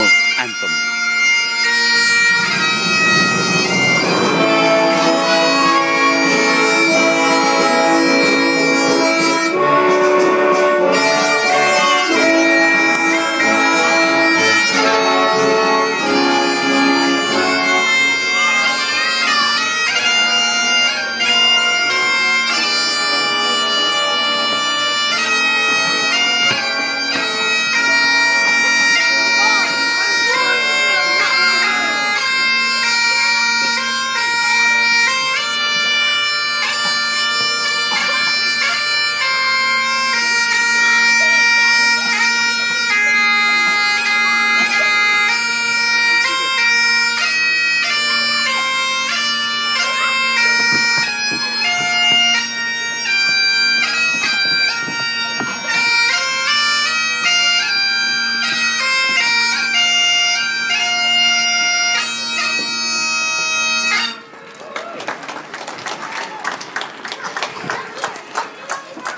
Irish National Anthem piped @ St Patrick's Day in Glounthaune Co Cork Ireland Europe Earth